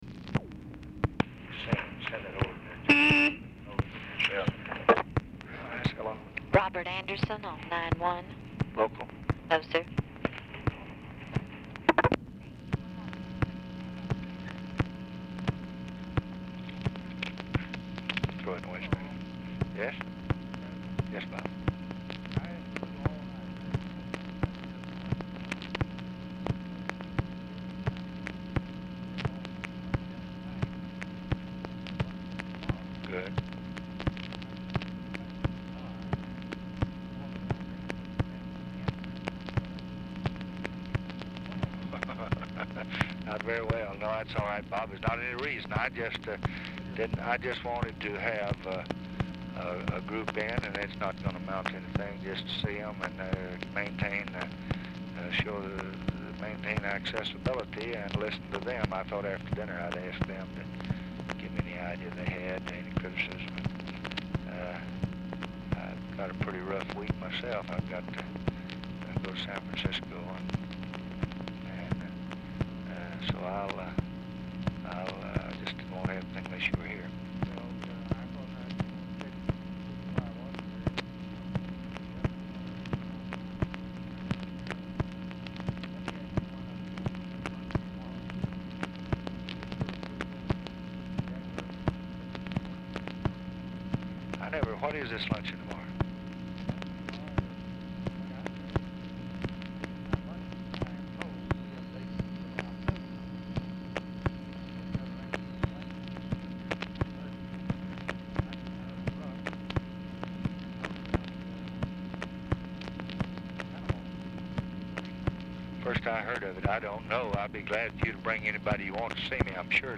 Telephone conversation # 8176, sound recording, LBJ and ROBERT ANDERSON, 6/22/1965, 11:50AM | Discover LBJ
Format Dictation belt
Location Of Speaker 1 Oval Office or unknown location